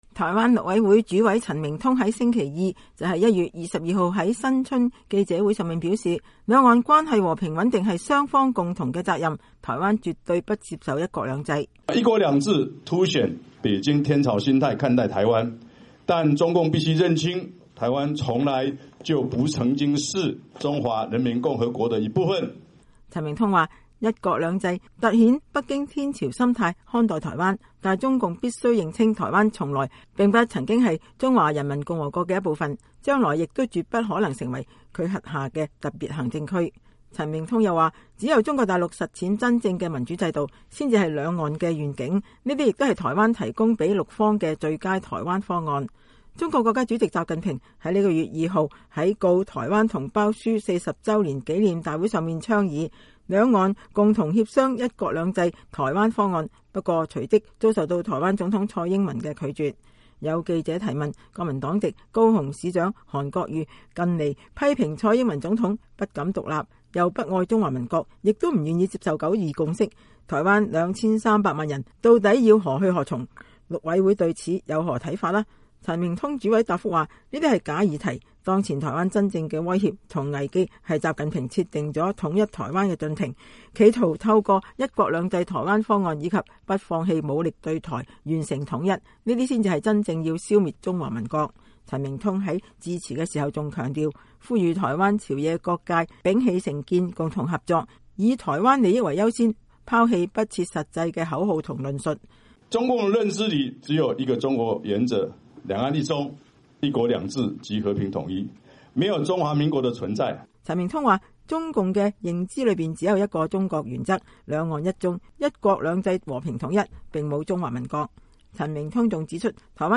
台灣陸委會主委陳明通星期二(1月22號)在新春記者會上表示，兩岸關係和平穩定是雙方共同的責任，台灣絕對不接受一國兩制。